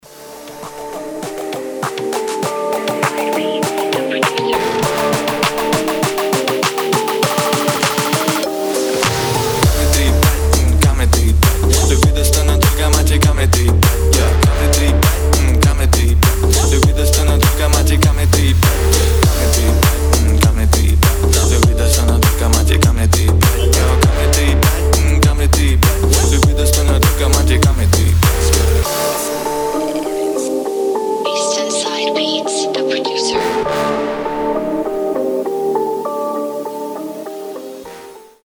• Качество: 320, Stereo
remix
мощные басы
нарастающие
качающие
G-House
четкие